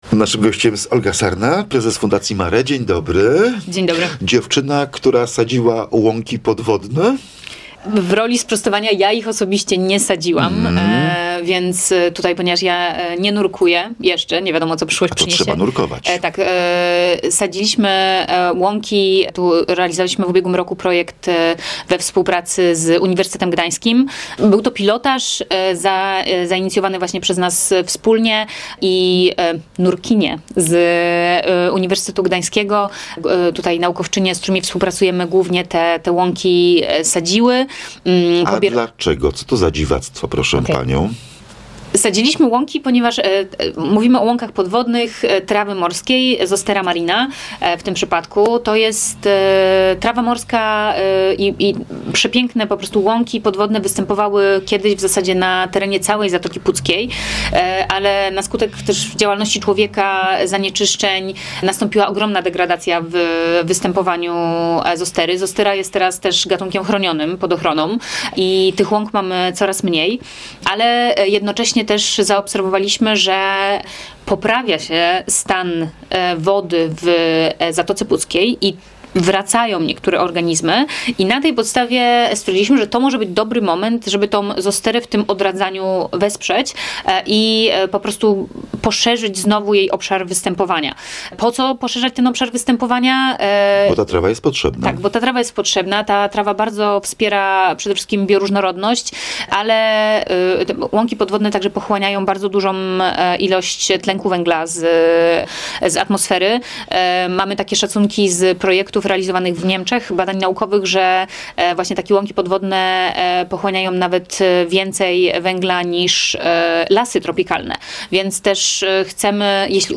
Posłuchaj rozmowy o podwodnych łąkach: https